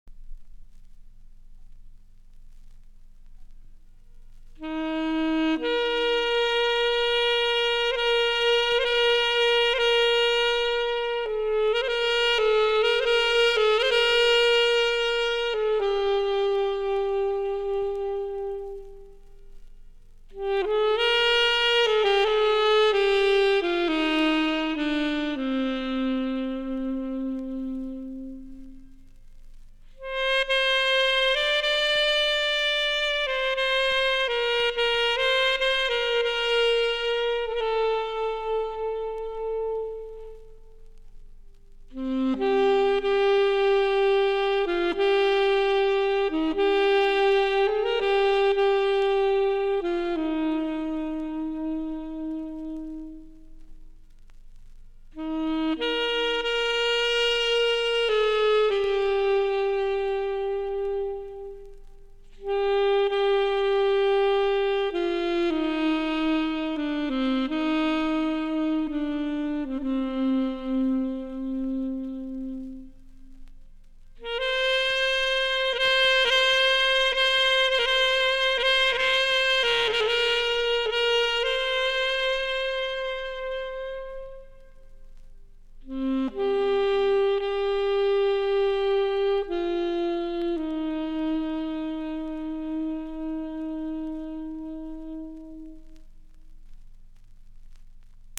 sax solo
April 1982 at Aquarius Studio, Geneva